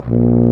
BRS F TUBA01.wav